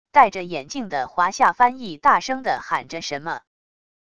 戴着眼镜的华夏翻译大声的喊着什么wav音频